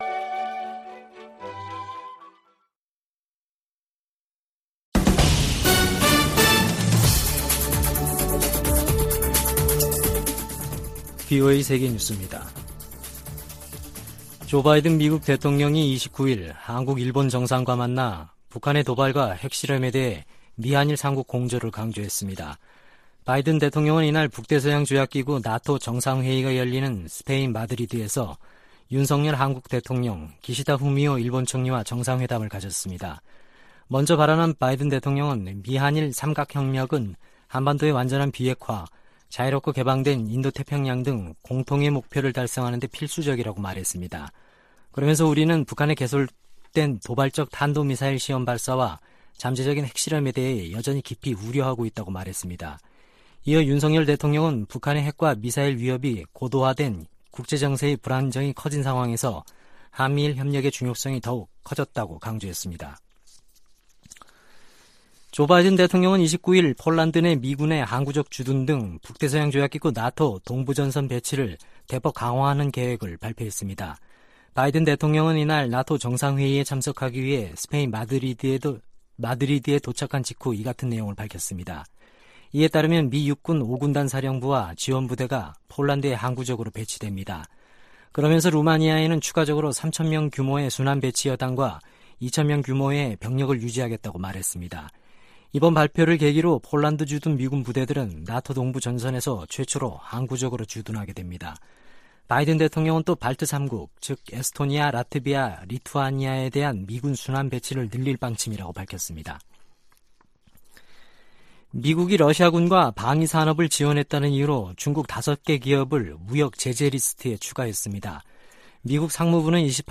VOA 한국어 아침 뉴스 프로그램 '워싱턴 뉴스 광장' 2022년 6월 30일 방송입니다. 제이크 설리번 백악관 국가안보보좌관은 나토 정상회의 현장에서 열리는 미한일 정상회담에서 대북 경제 압박 방안이 논의될 것이라고 말했습니다. 옌스 스톨텐베르그 나토 사무총장은 새 전략개념을 제시하면서 중국과 러시아의 도전을 견제하겠다는 의지를 확인했습니다. 한국은 국제 규범과 법치, 인권 문제 등에서 나토에 기여할 수 있다고 미국 전직 관리들이 말했습니다.